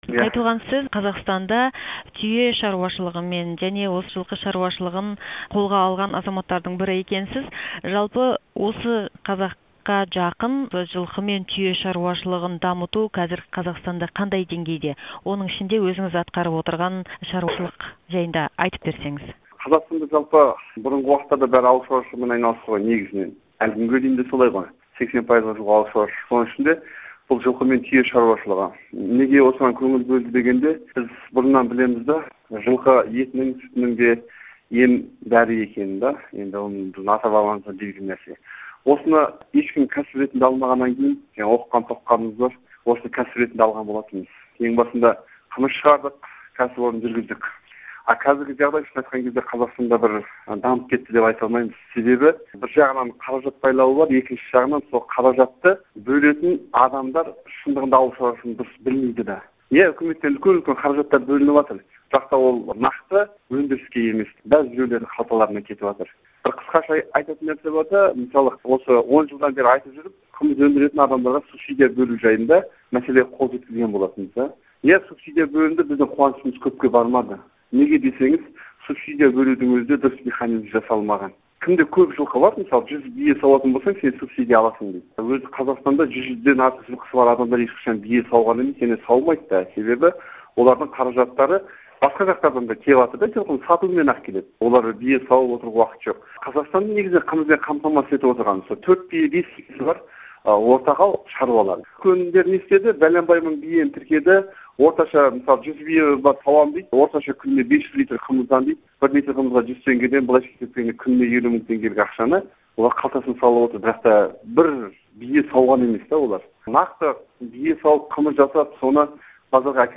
сұқбат